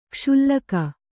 ♪ kṣullaka